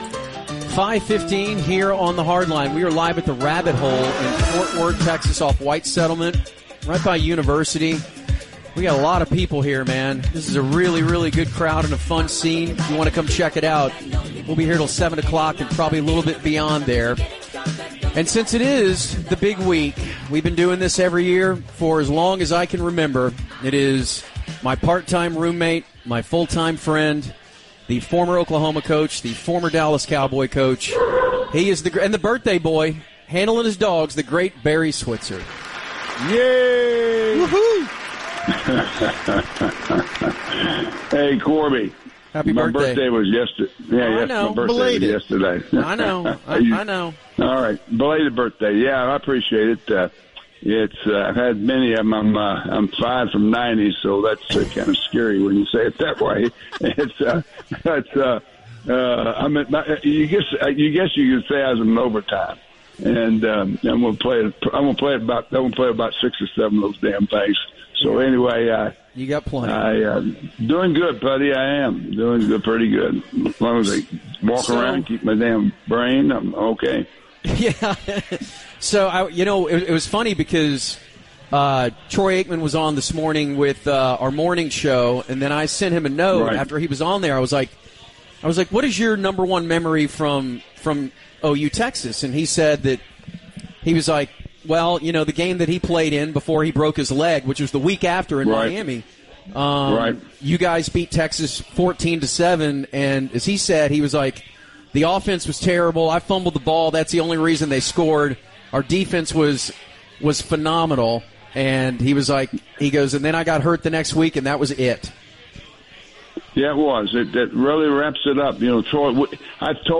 Barry joins the Hardline in preparation for the TX/OU game. He has a special call in from fake Jerry Jones